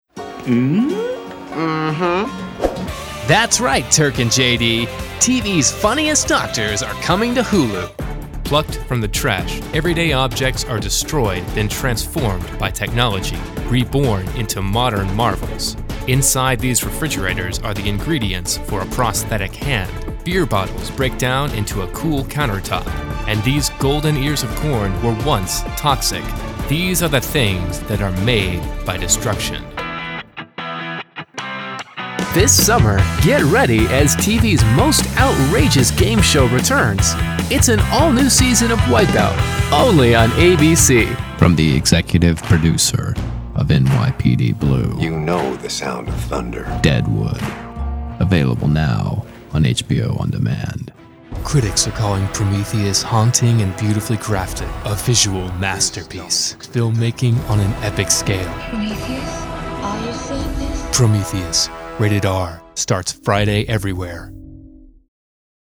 Male
Movie Trailers
Scrubs Promo, Funny